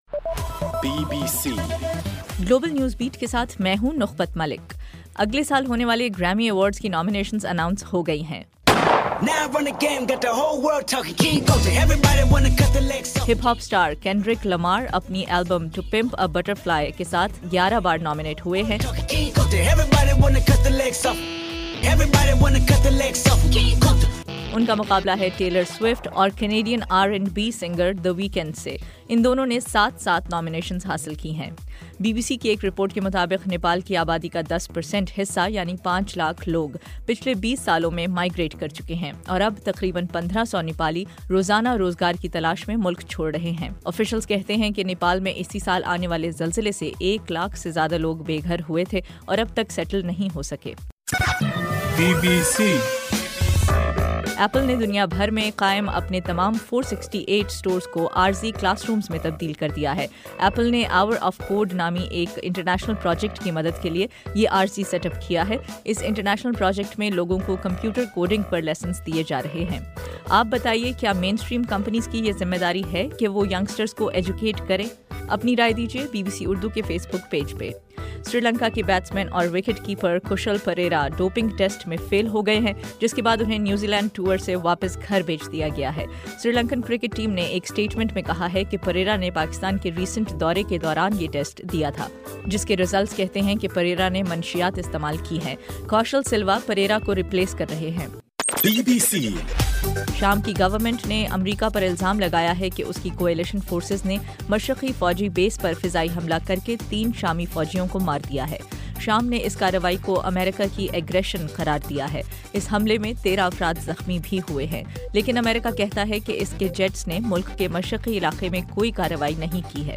دسمبر 7: رات 11بجے کا گلوبل نیوز بیٹ بُلیٹن